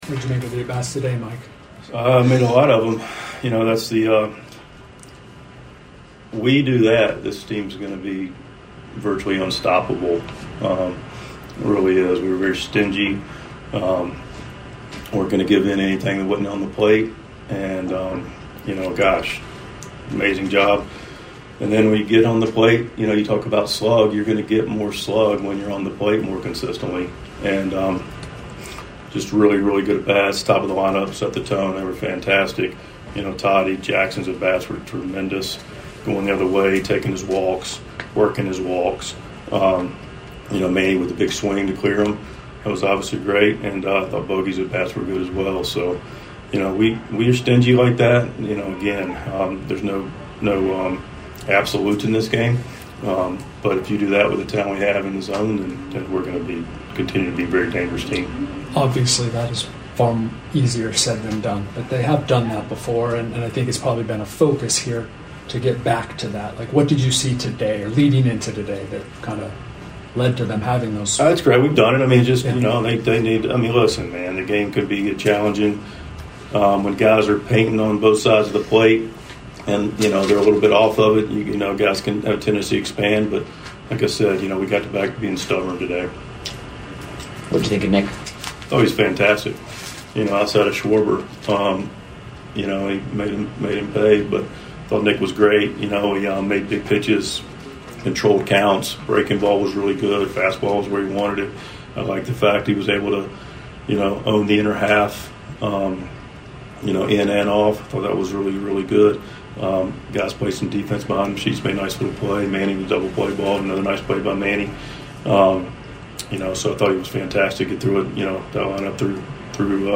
Mike Shildt's postgame reaction after Game 1's 6-4 win over the Phillies.